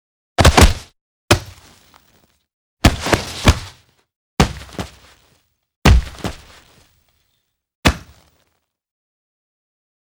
Âm thanh Đánh một Nắm đấm và Rơi xuống đất